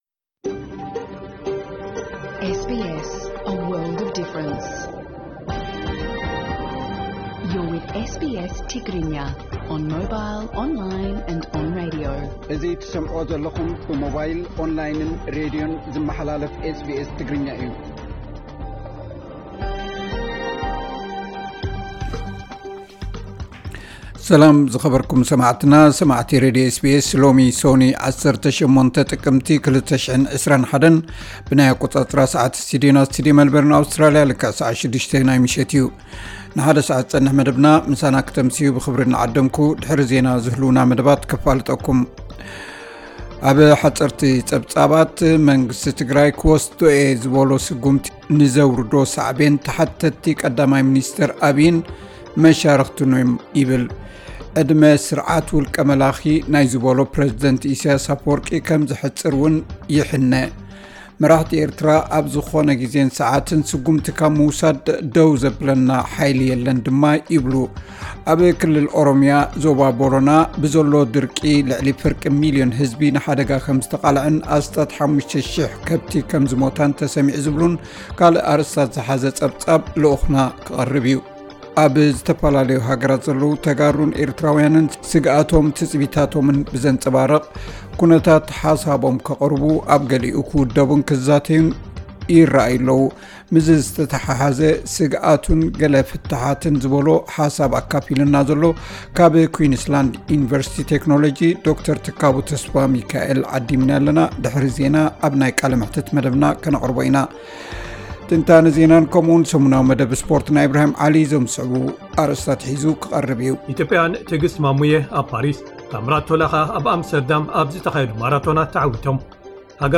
ዕለታዊ ዜና SBS ትግርኛ (18 ጥቅምቲ 21)